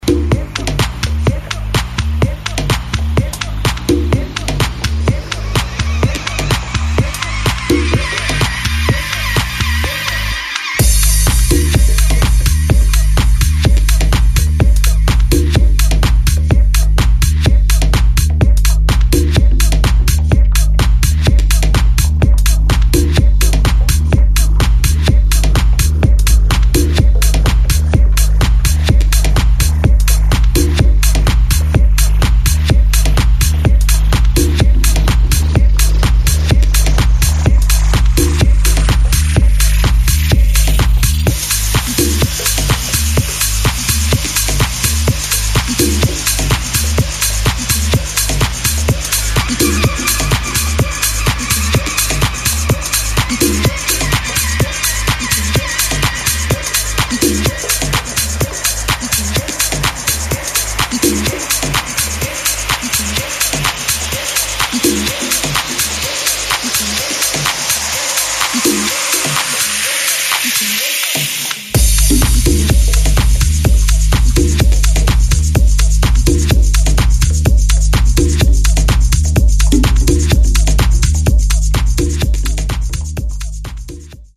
[ TECHNO | HOUSE ]